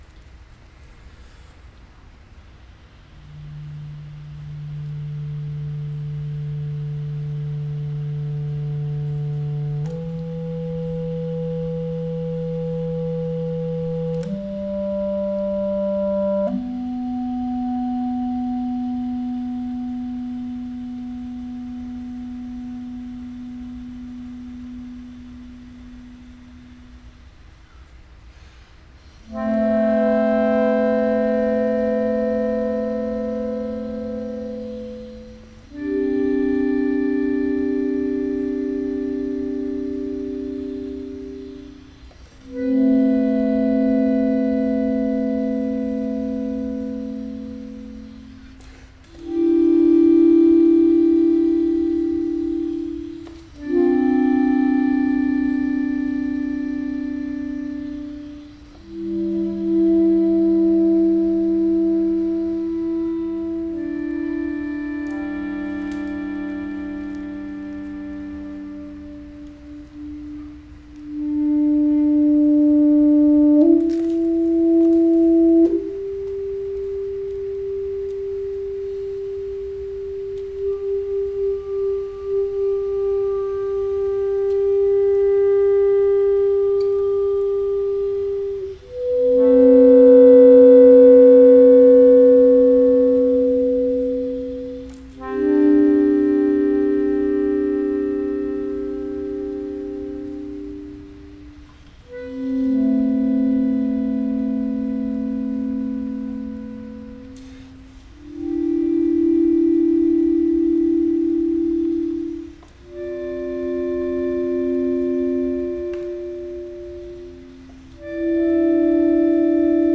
saxophone, clarinet and accordion